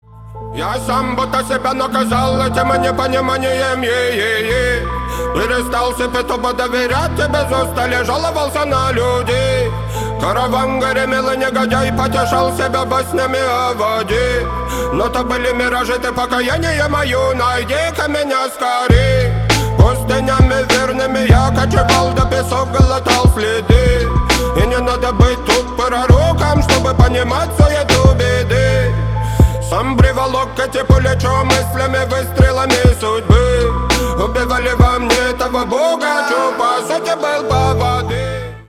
хип-хоп , Рэп рингтоны